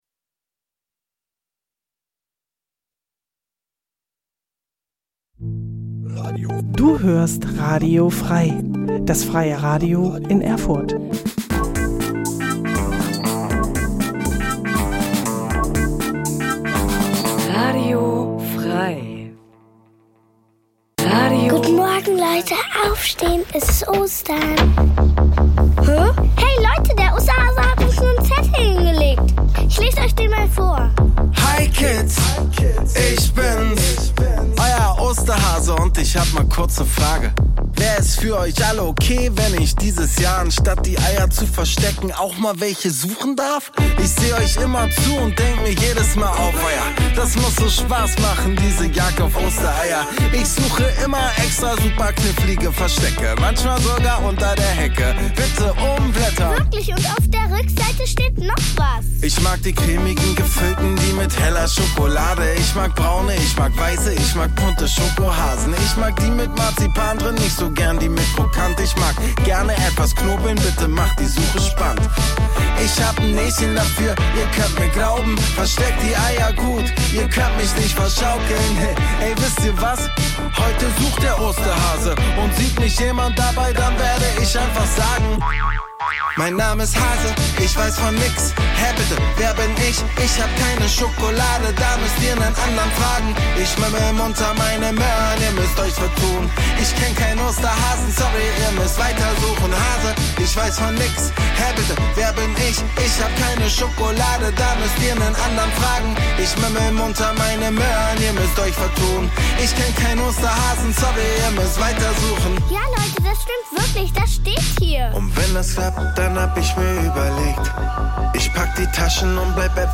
Musik f�r Kids